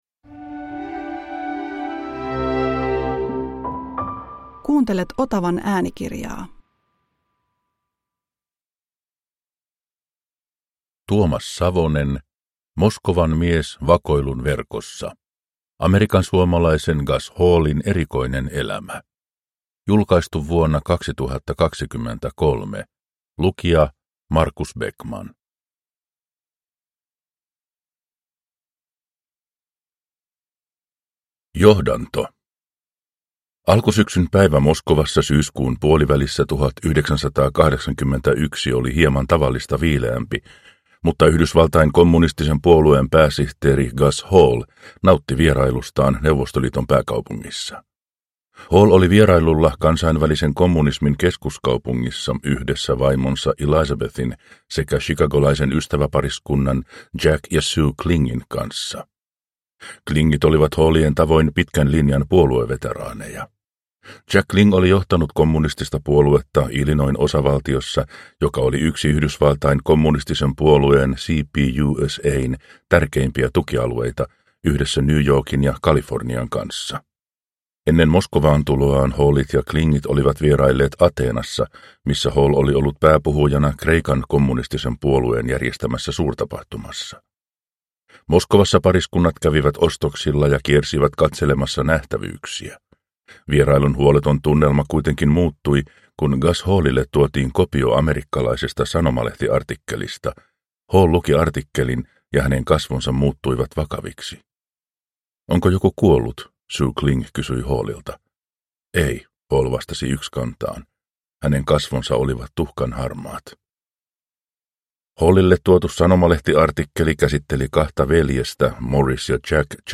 Moskovan mies vakoilun verkossa – Ljudbok – Laddas ner